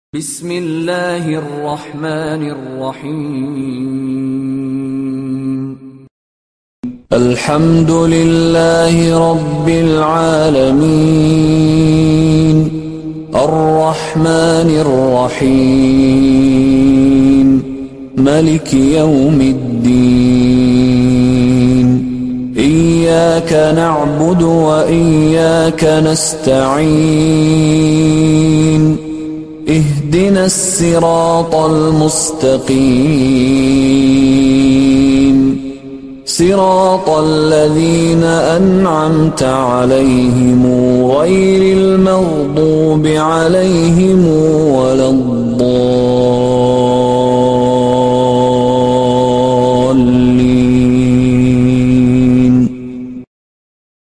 The always beautiful recitation of Surah Al Fatihah by Sheikh Mishary al-Afasy.